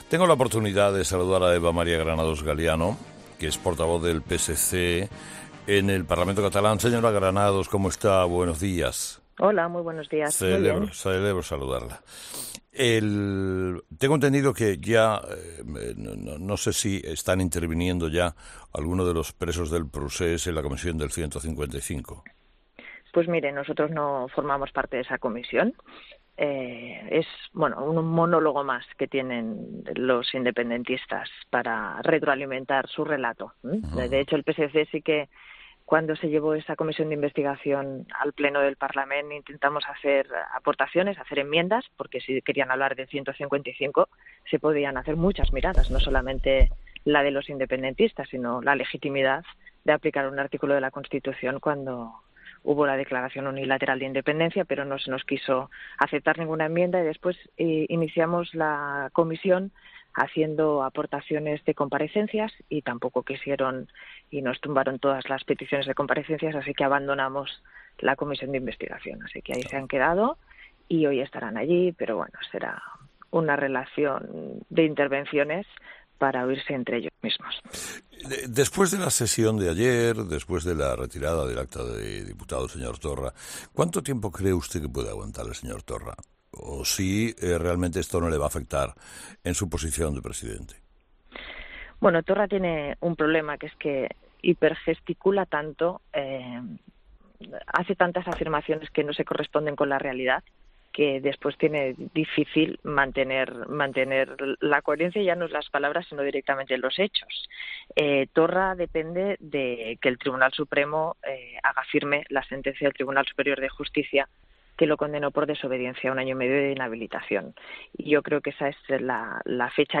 Eva Granados, portavoz del PSC en el Parlamento de Cataluña, ha sido entrevistada este martes en 'Herrera en COPE' después del divorcio entre ERC y JxCat que acerca a Cataluña a elecciones adelantadas.